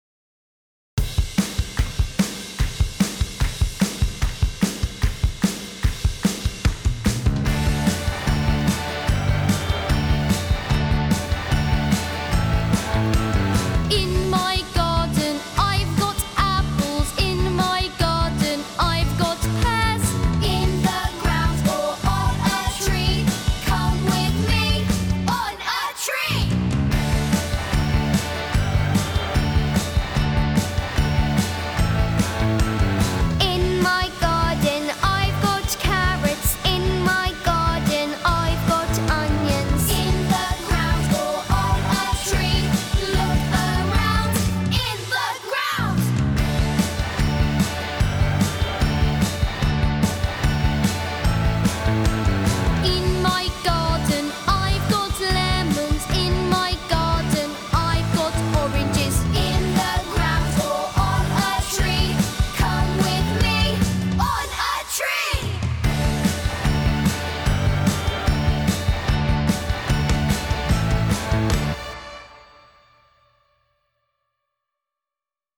Song